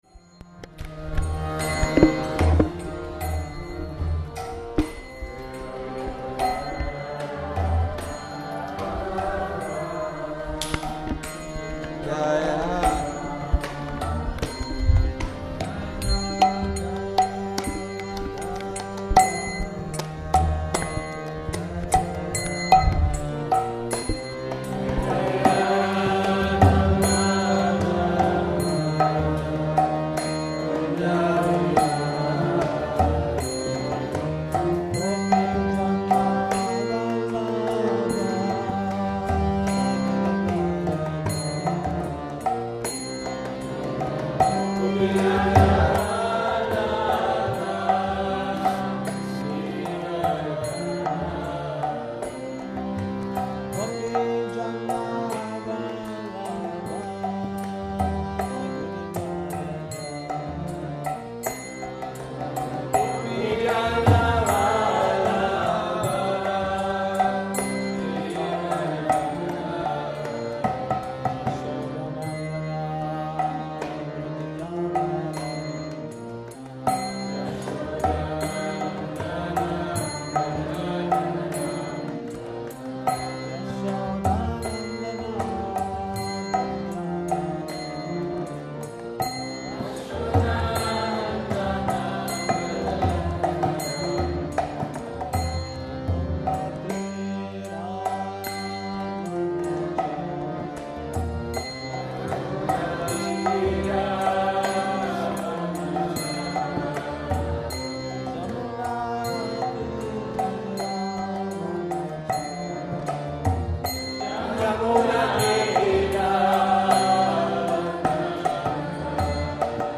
Bhajanok